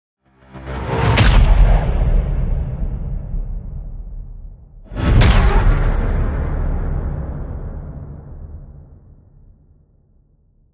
bong.mp3